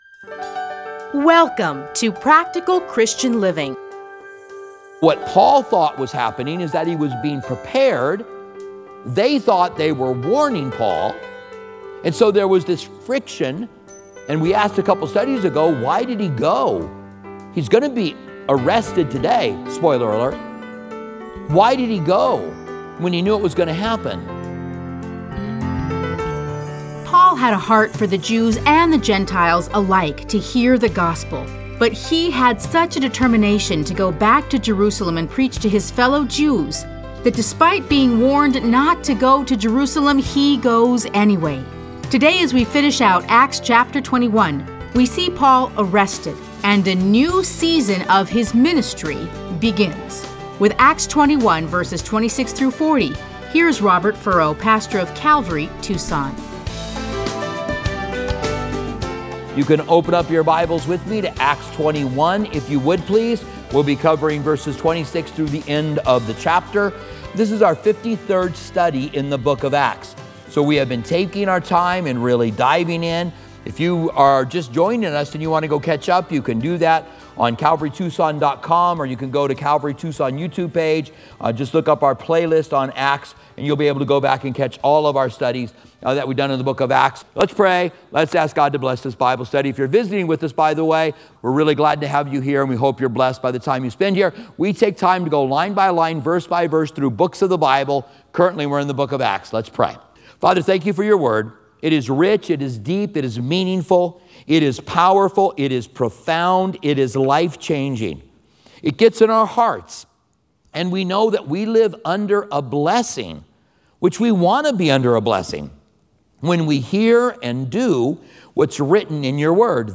Listen to a teaching from Acts 21:26-40.